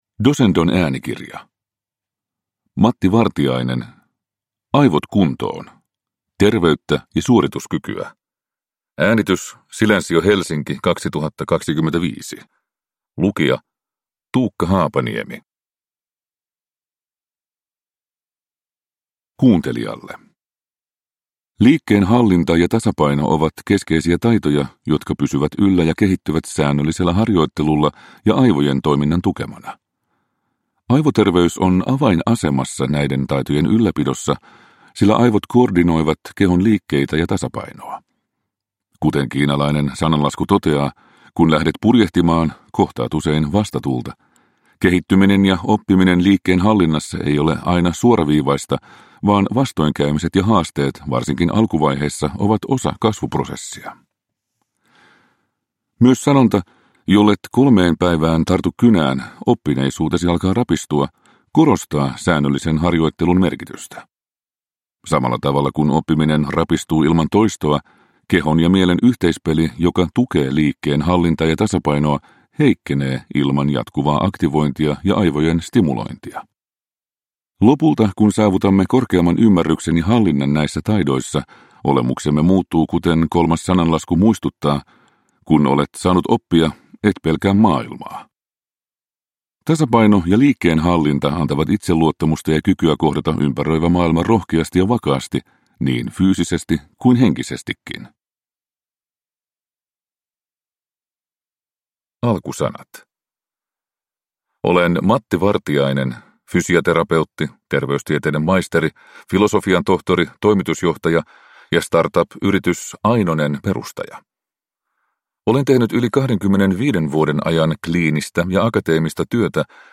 Aivot kuntoon – Ljudbok